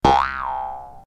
bounce1.ogg